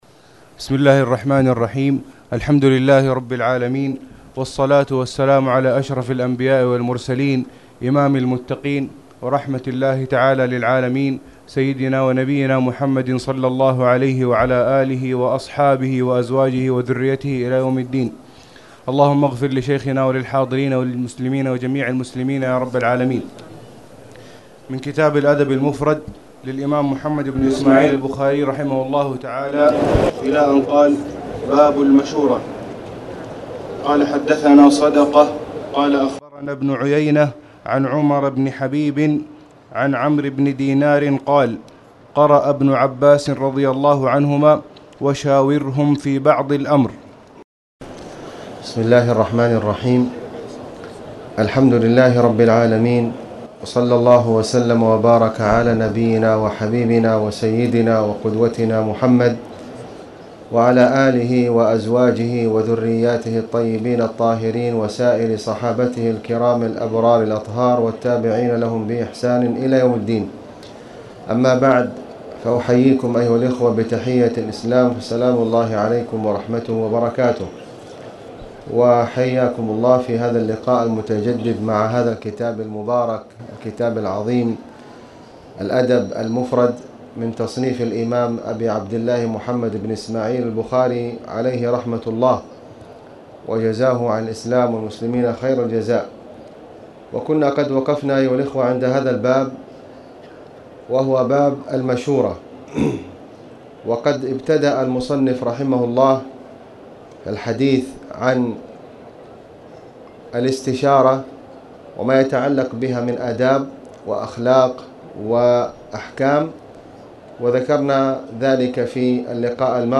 تاريخ النشر ١٦ جمادى الأولى ١٤٣٨ هـ المكان: المسجد الحرام الشيخ: خالد بن علي الغامدي خالد بن علي الغامدي باب الإستشارة